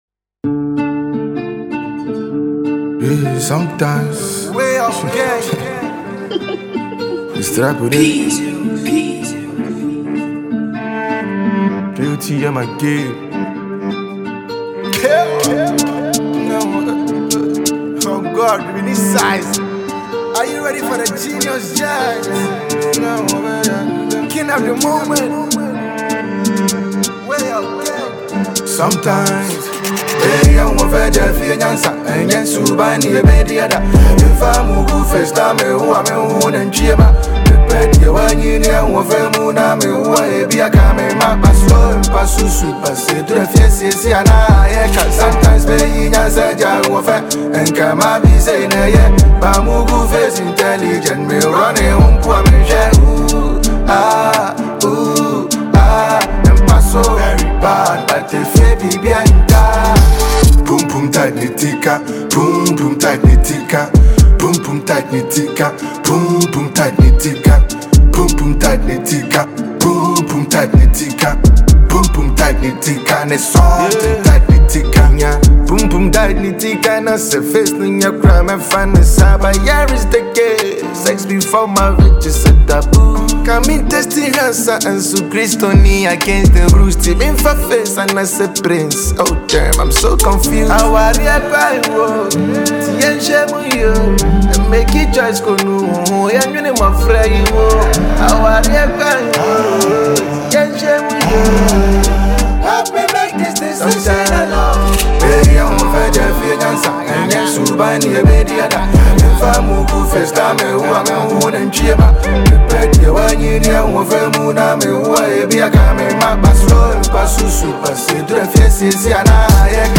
a Ghanaian asakaa artist